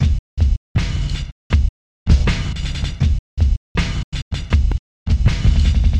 描述：它的速度比80bpm快，不是吗？
标签： 80 bpm Drum And Bass Loops Drum Loops 1.10 MB wav Key : Unknown
声道立体声